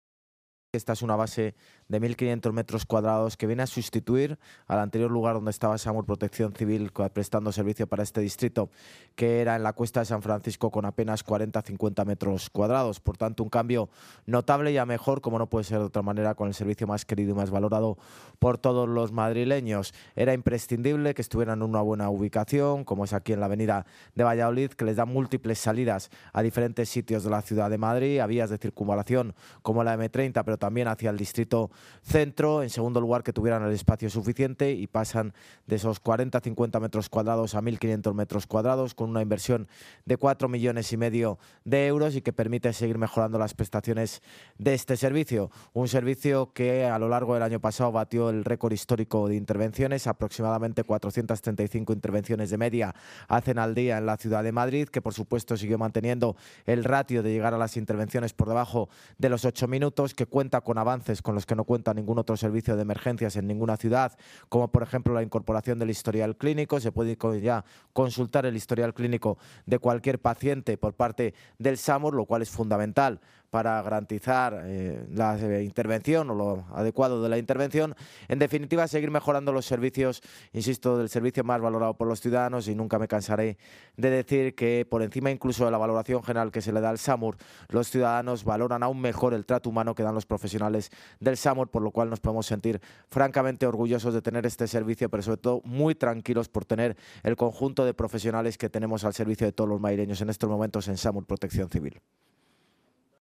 El alcalde, José Luis Martínez-Almeida, ha repasado la actividad de este servicio municipal en 2022, durante su visita a la nueva base de Moncloa-Aravaca
Nueva ventana:José Luis Martínez-Almeida, alcalde de Madrid
JLMartinezAlmeida-NuevaBaseSAMURMoncloa-27-01.mp3